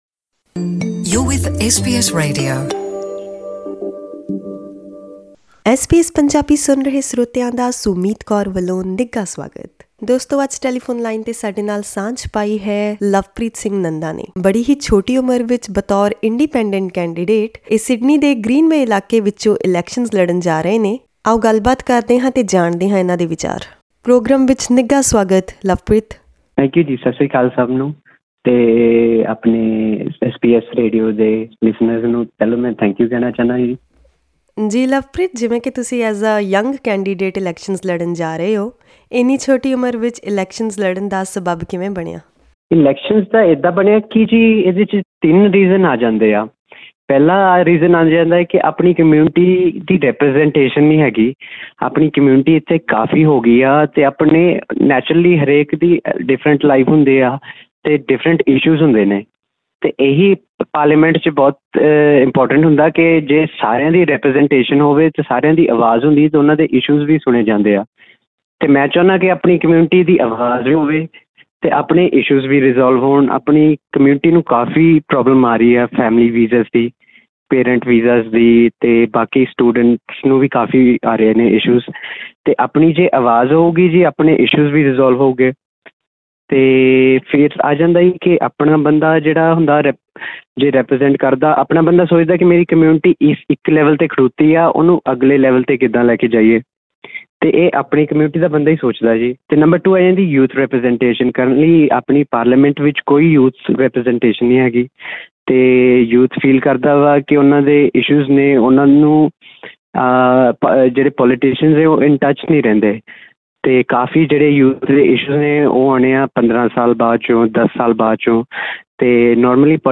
Click on the audio player to listen to this conversation in Punjabi.